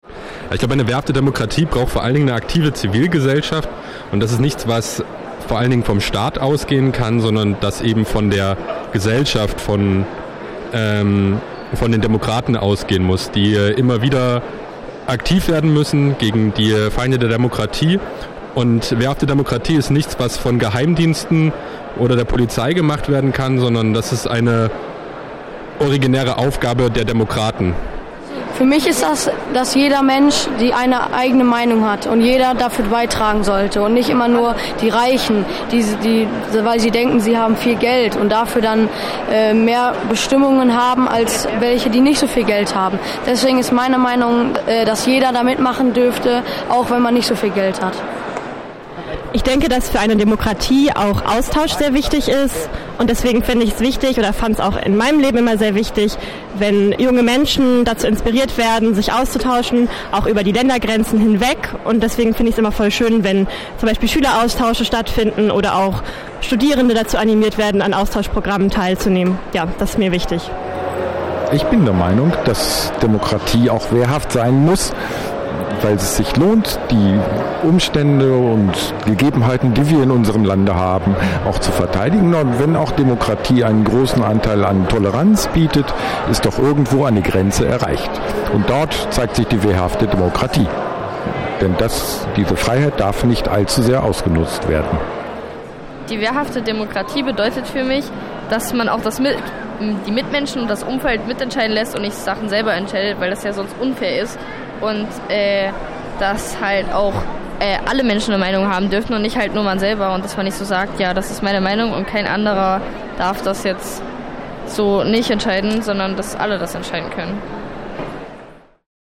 Impressionen aus unserer Sprechkabine auf dem Evangelischen Kirchentag (Downloadlink mit 930 kB).
collage-sprechkabine-kirchentage-100.mp3